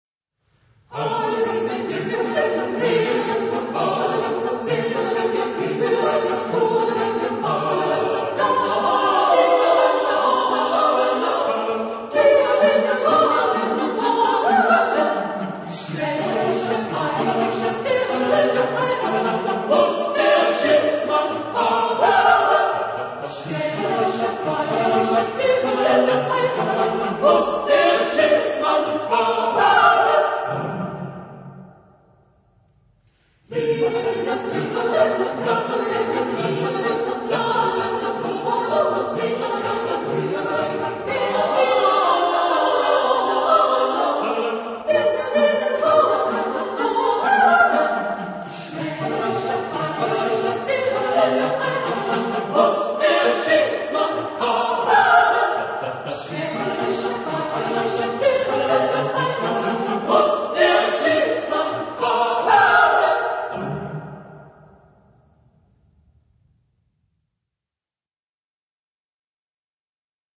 Género/Estilo/Forma: Folklore ; Canción ; Profano
Tipo de formación coral: SAATB  (5 voces Coro mixto )
Tonalidad : re mayor